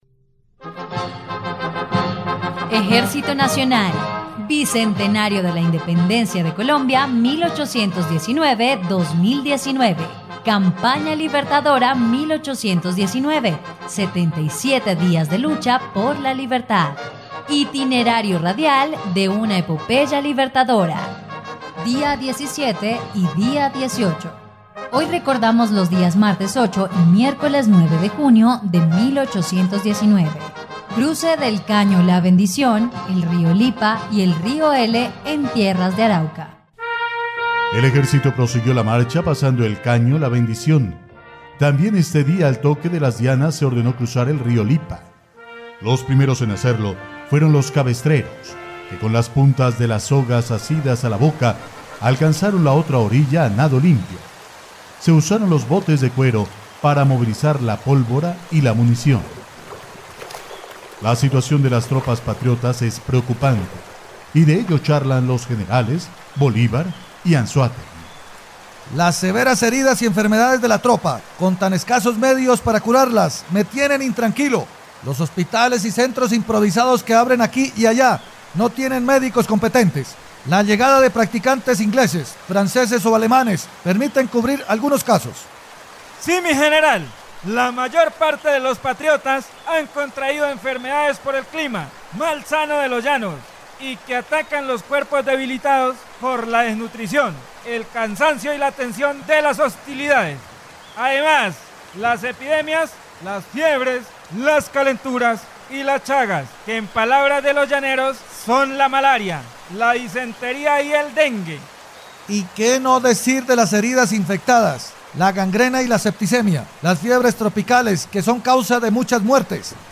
dia_17_y_18_radionovela_campana_libertadora.mp3